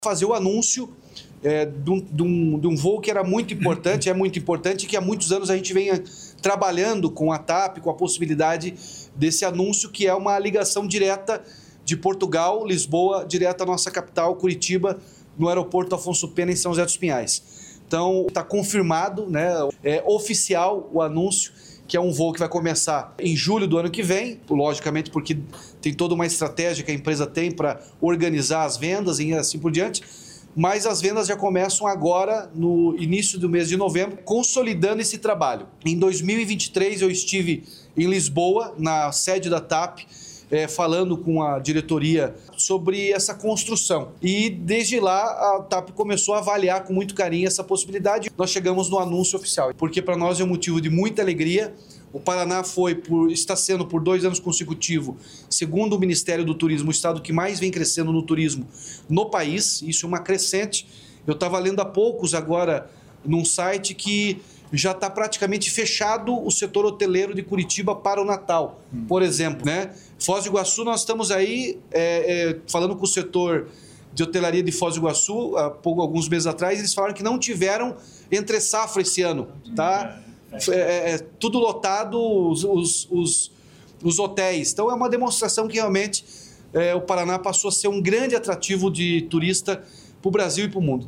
Sonora do governador Ratinho Junior sobre o voo direto de Curitiba a Lisboa